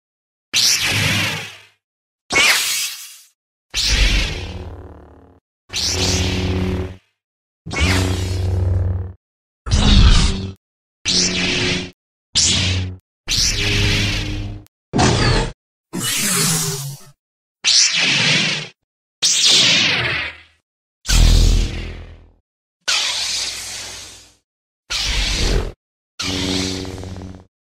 Звуки лазерного оружия
Драка световыми мечами